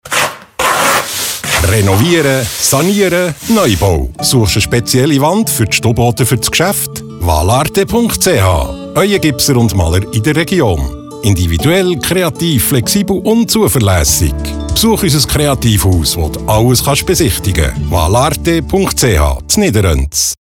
Aktuell: Werbespot im Radio 32 – horen Sie rein!